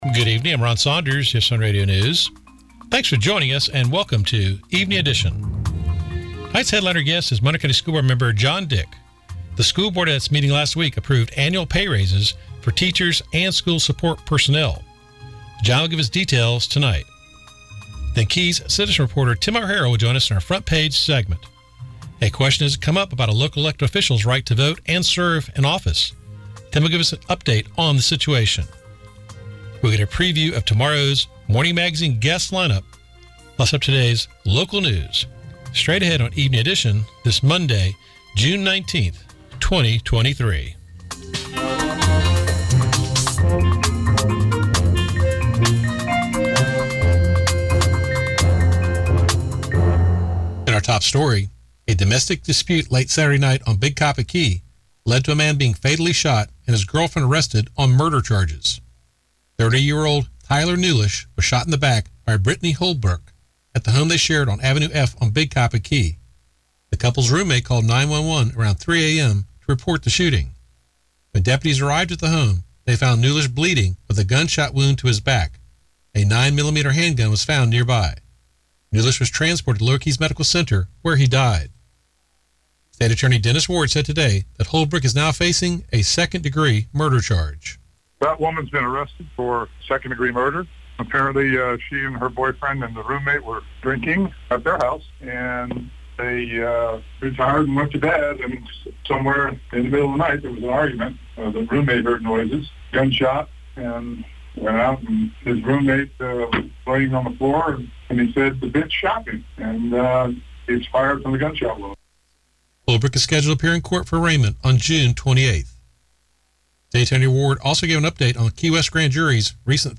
» Evening Edition Discussed Commissioner Harding on June 19, 2023. The popular US1 Radio talk show